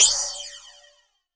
Crash OS 04.wav